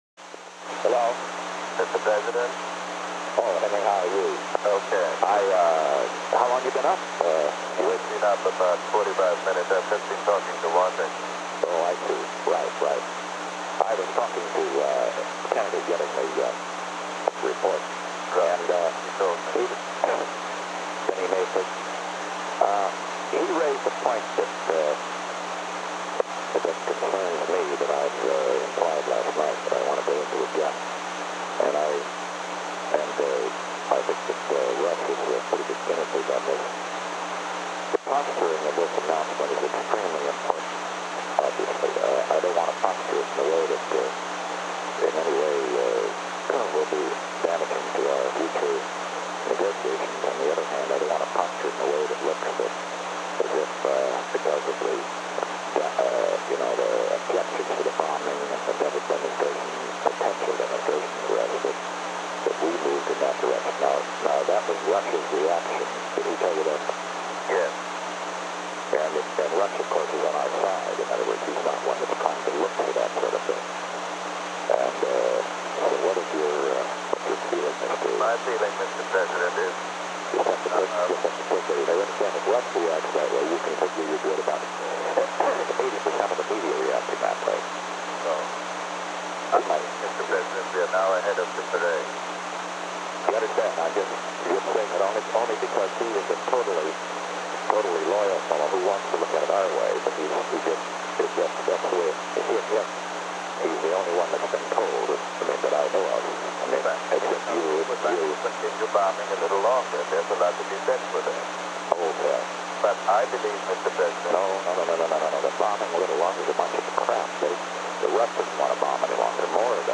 Conversation No. 158-9 Date: December 29, 1972 Time: 11:24 am - 11:46 am Location: Camp David Study Table The President talked with Henry A. Kissinger.
Secret White House Tapes